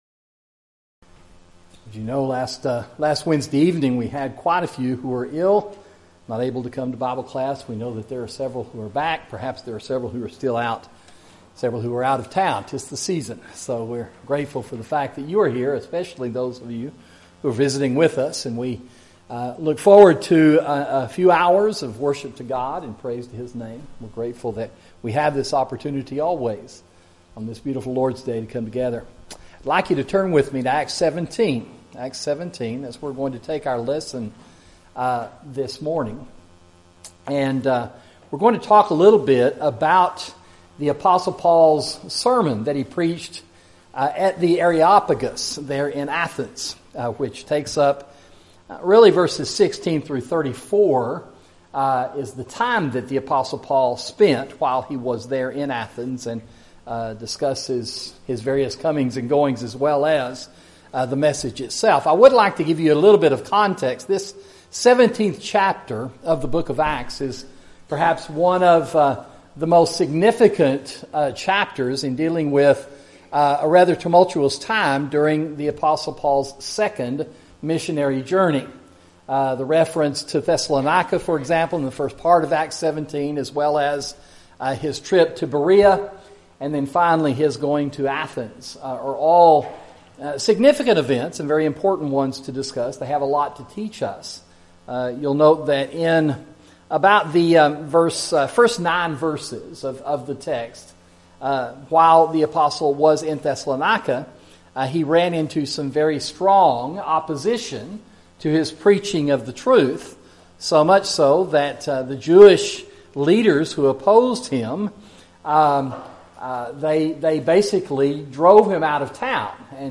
Sermon: Preaching at the Areopagus (Acts 17)